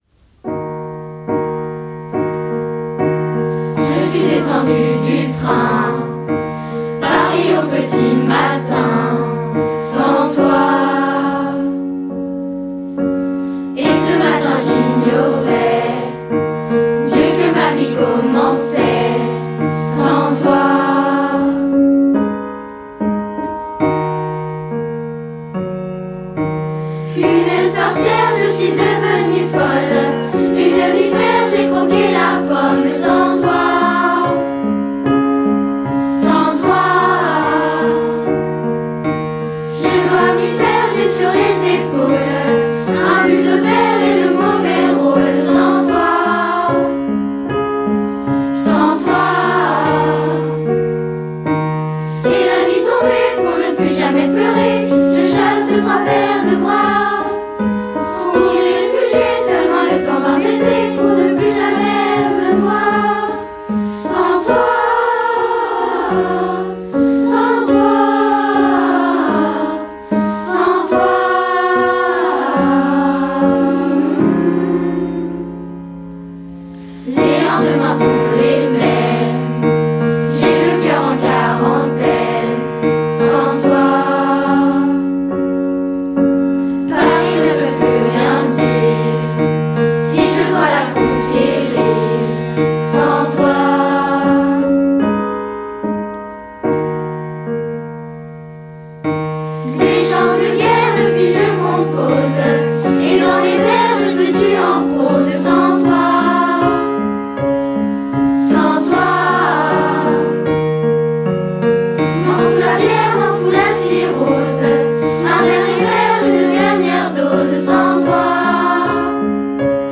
En effet, les élèves de la chorale pendant cette semaine culturelle ont enregistré 7 chansons apprises cette année, afin de réaliser un CD.